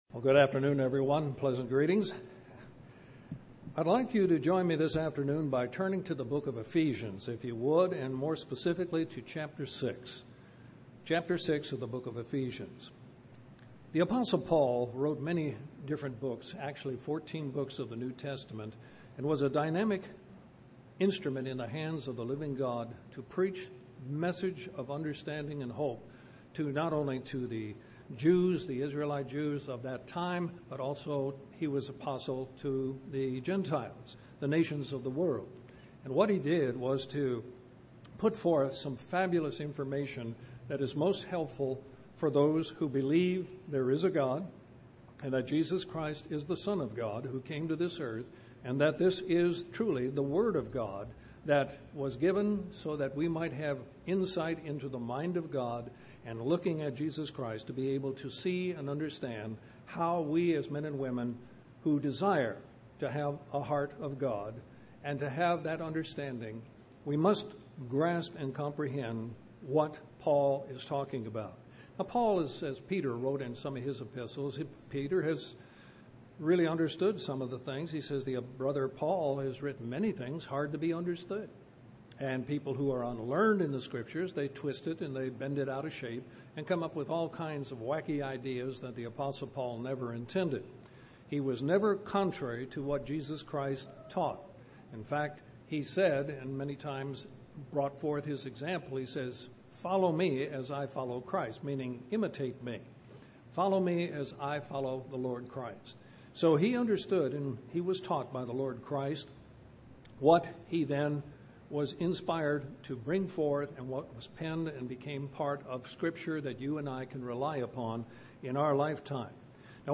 Given in Columbus, GA Central Georgia
UCG Sermon Studying the bible?